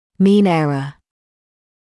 [miːn ‘erə][миːн ‘эрэ]средняя ошибка, средняя погрешность